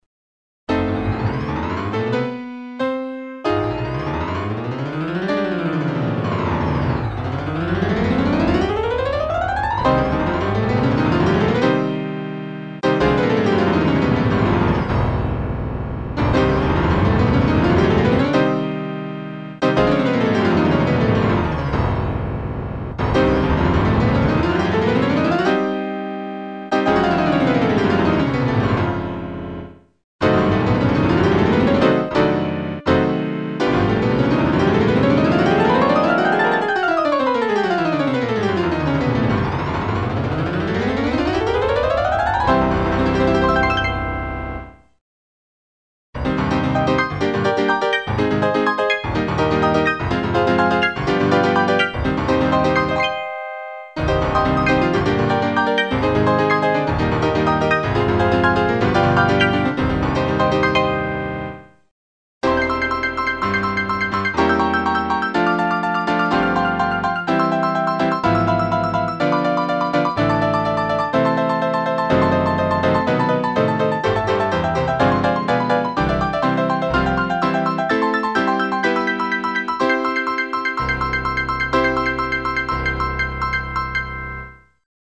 It also plays musical sequences that challenge the piano dampers.
MP3 audio file of the portion of the roll that follows the Note Repetition Test, Scale Uniformity Test, and Capacity Test, the roll is designed to find out if the Melody can be heard during times when lots of other notes are being played.